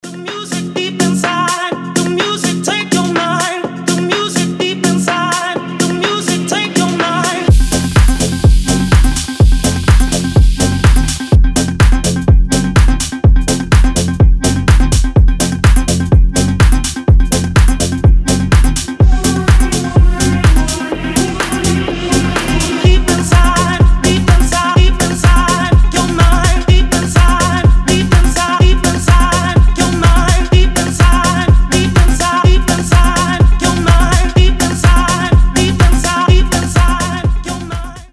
• Качество: 320, Stereo
ритмичные
Electronic
EDM
Tech House
Офигенский тек-хаус